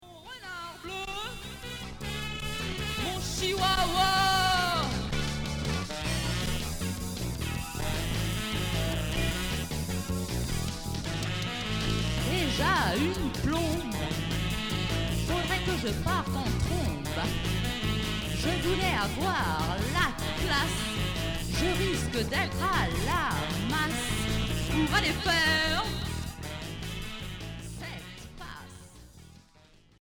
Ska rock